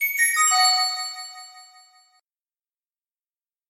Звук включения устройства nШум запуска девайса nЗвуковой сигнал старта устройства